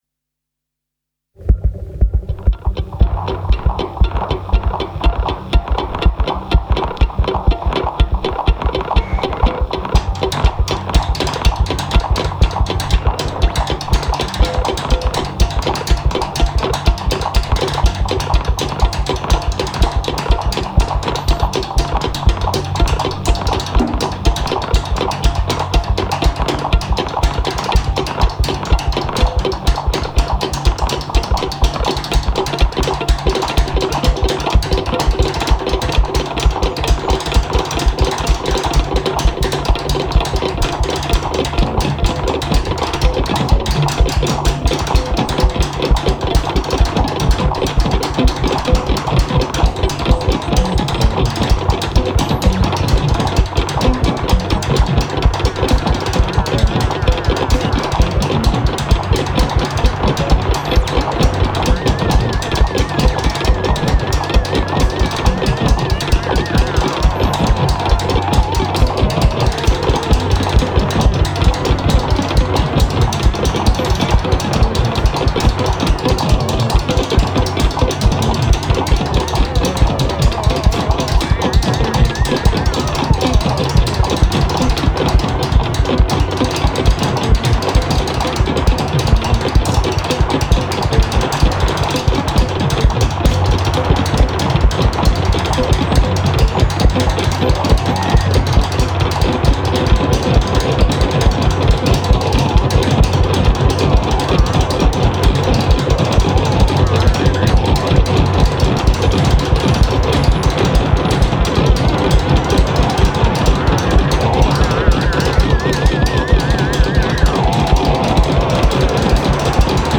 genre: lightcore